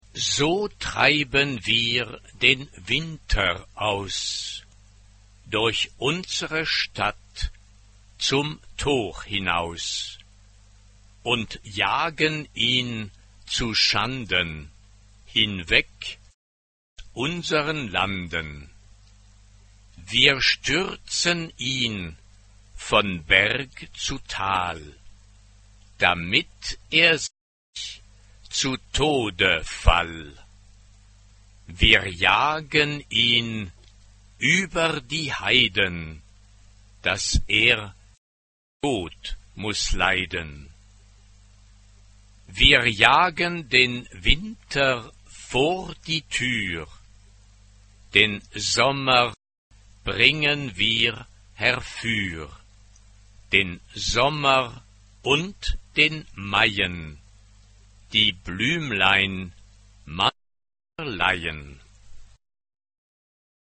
SSAA (4 voices women) ; Full score.
Folk music.
Renaissance.
Type of Choir: SSAA (4 women voices )
Tonality: E minor